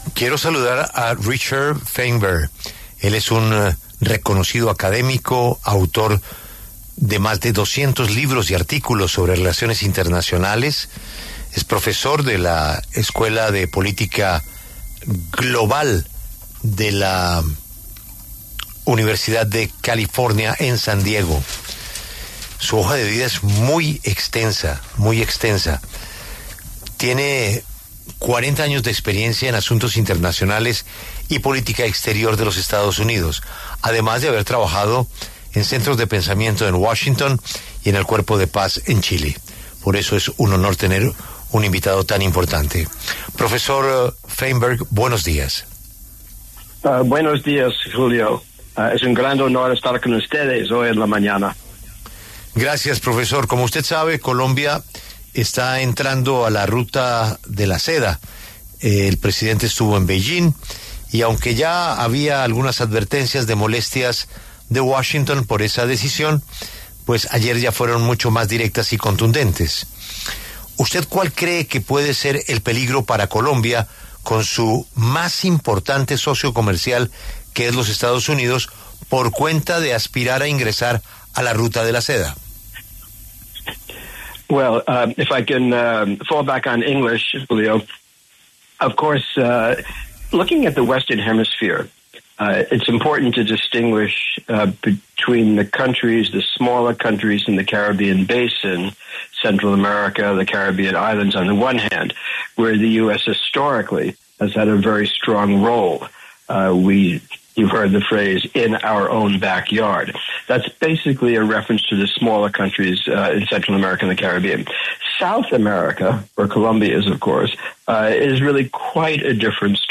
diplomático, asesor político y académico